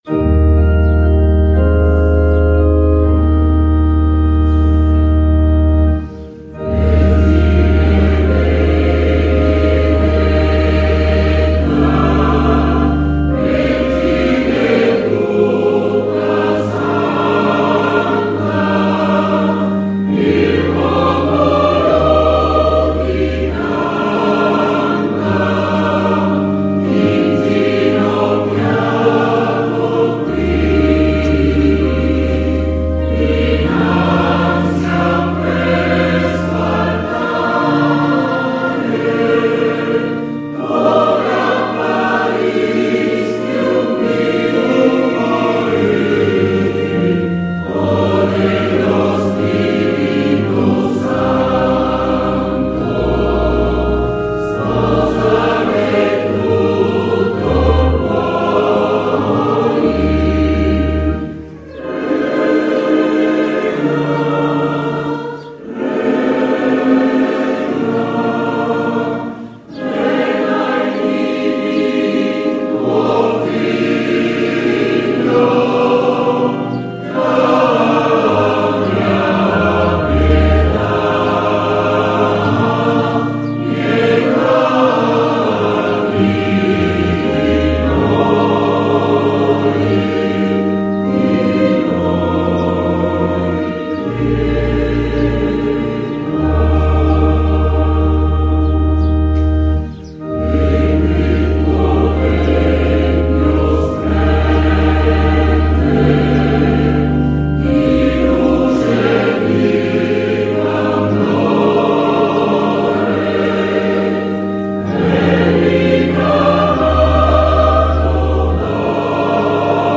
Coro Polifonico